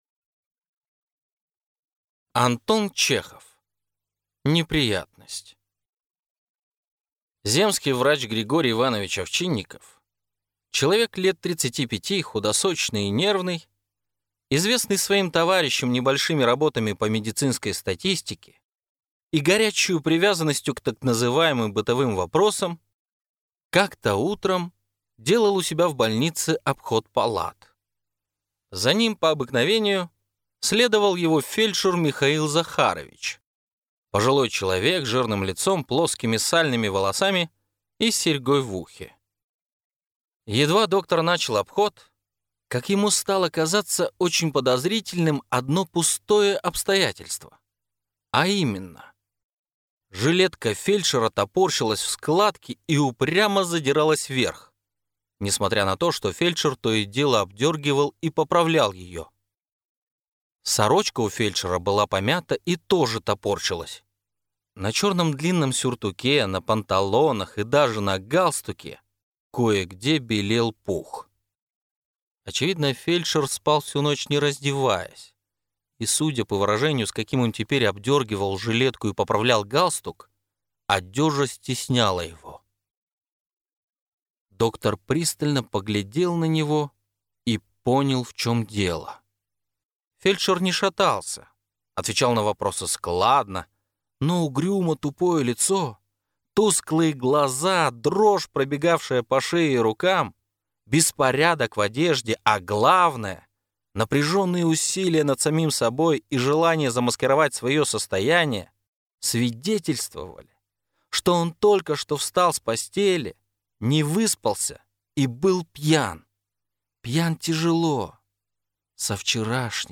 Аудиокнига Неприятность